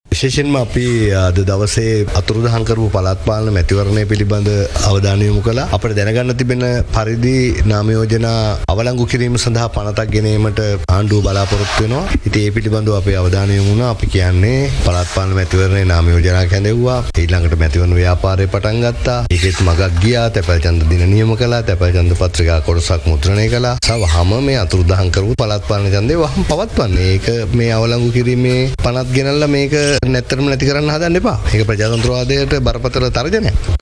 මේ පිළිබදව එම පක්ෂයේ පැවති සාකච්ඡාවකින් අනතුරුව මාධ්‍ය වෙත අදහස් දක්වමින් පාර්ලිමේන්තු මන්ත්‍රී ජයන්ත සමරවීර මහතා මෙලෙස අදහස් දක්වා සිටියා.